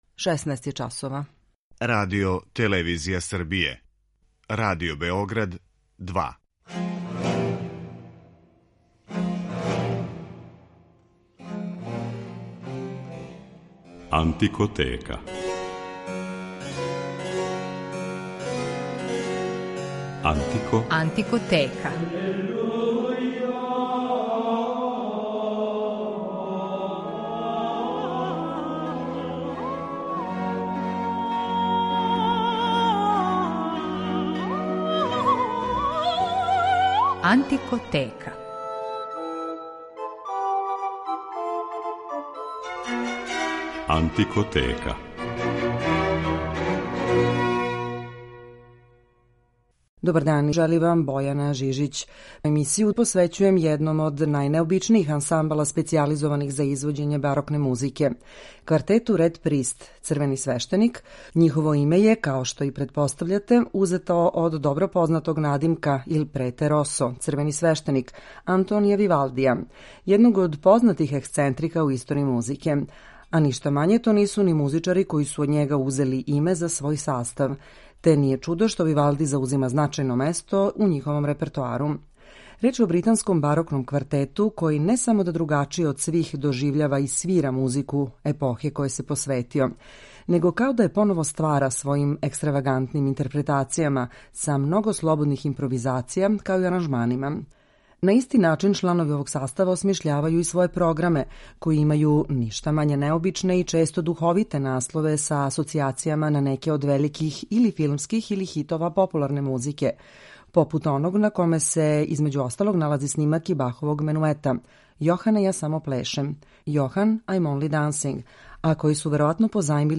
ексцентричним, маштовитим па и шокантним извођењима
свирају на инструментима из епохе или њиховим копијама
британском барокном квартету